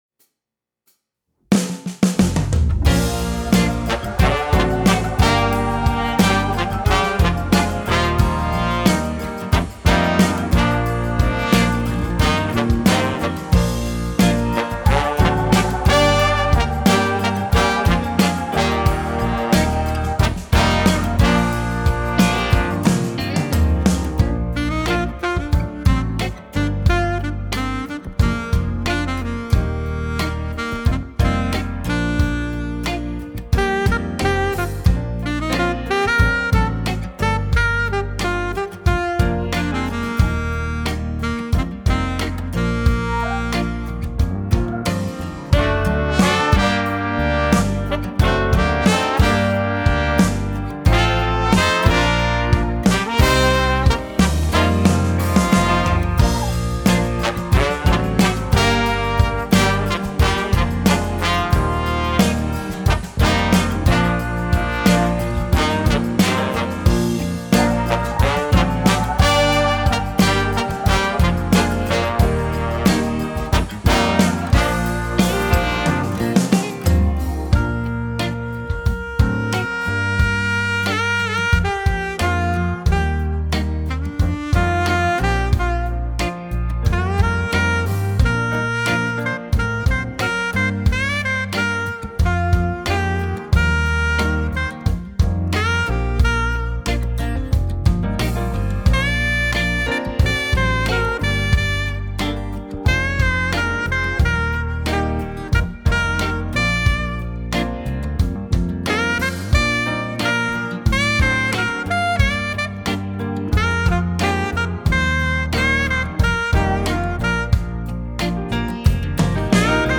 Saxophone Alto